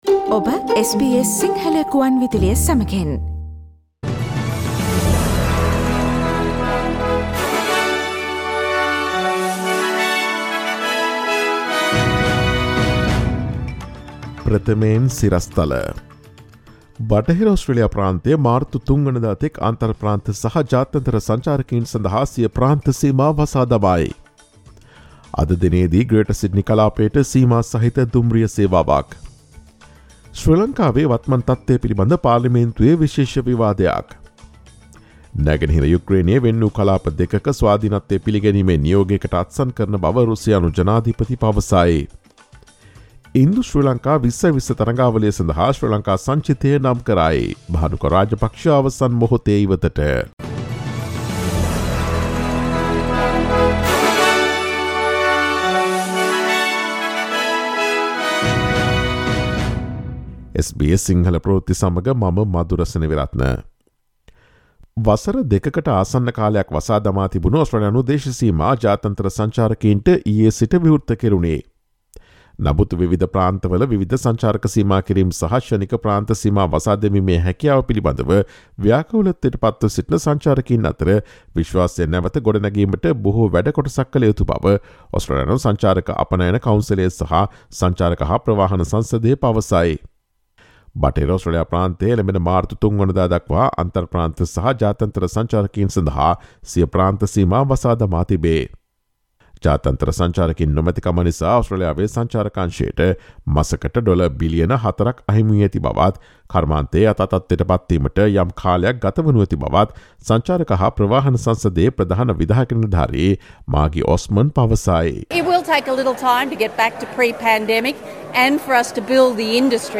ඔස්ට්‍රේලියාවේ සහ ශ්‍රී ලංකාවේ නවතම පුවත් මෙන්ම විදෙස් පුවත් සහ ක්‍රීඩා පුවත් රැගත් SBS සිංහල සේවයේ 2022 පෙබරවාරි 22 වන දා අඟහරුවාදා වැඩසටහනේ ප්‍රවෘත්ති ප්‍රකාශයට සවන් දීමට ඉහත ඡායාරූපය මත ඇති speaker සලකුණ මත click කරන්න.